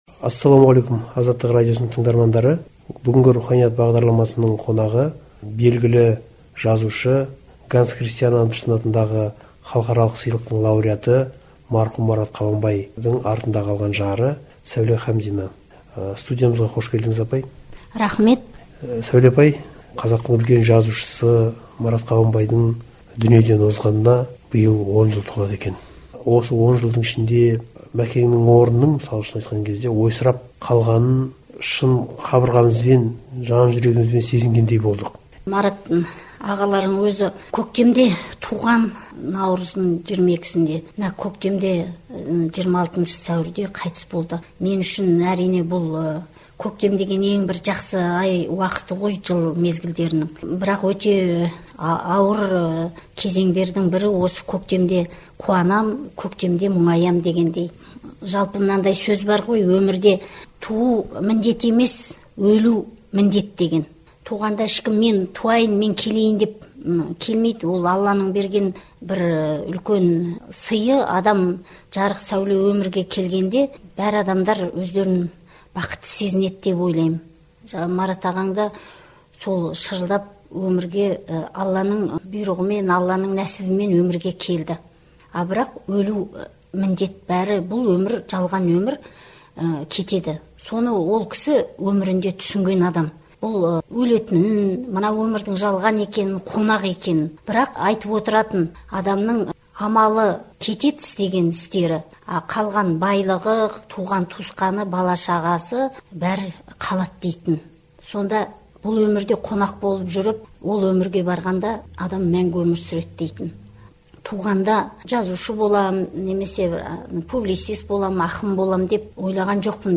сұхбат